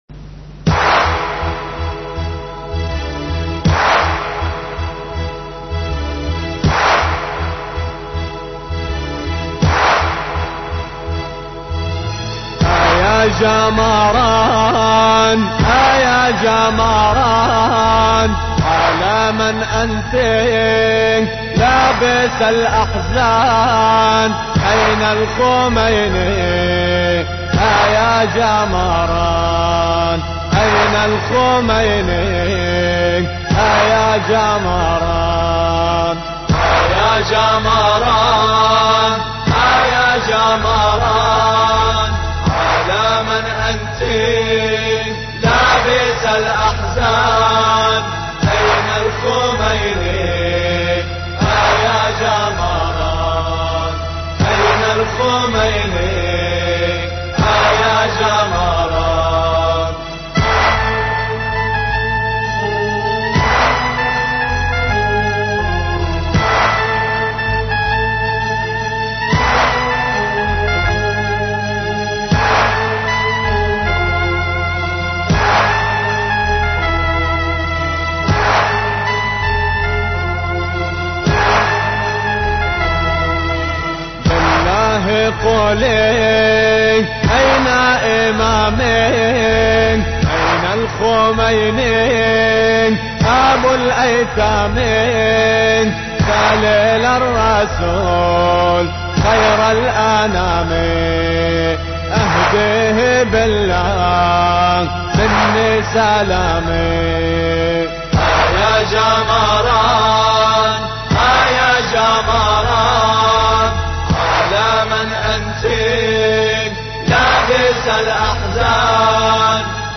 أناشيد لبنانية .... أيا جماران